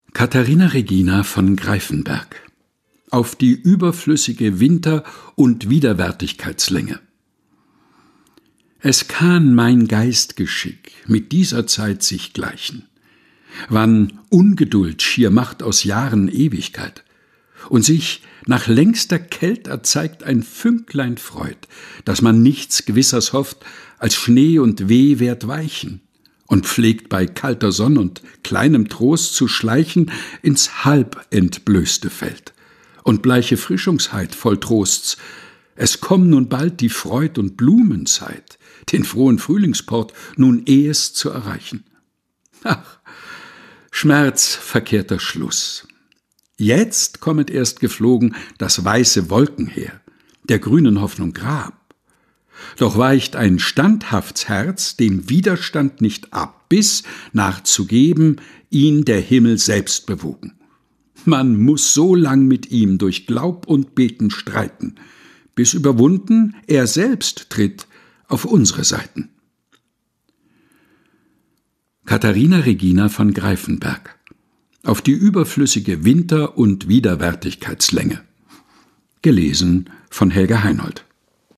Texte zum Mutmachen und Nachdenken - vorgelesen